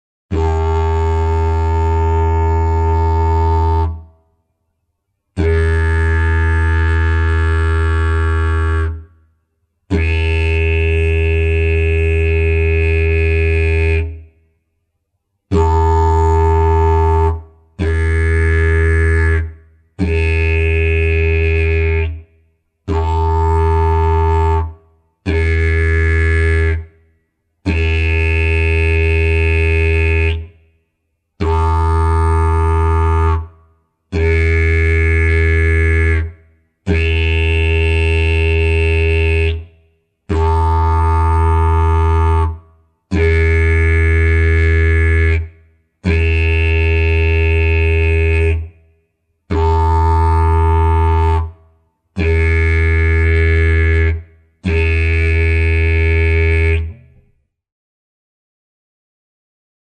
9.1 Armonici
Sample n°5 contiene: armonici divisi nelle tre posizioni illustrate.